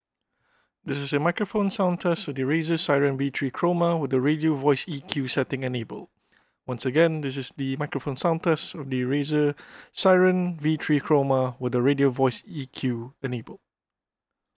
We got a few recordings down with the Razer Seiren V3 Chroma to show how well it performs.
For the 2nd recording, it’s with the Radio EQ setting on Razer Synapse enabled. While the standard recording sounded warm and with depth to the voice, this one sounded rather flat albeit still pretty clear.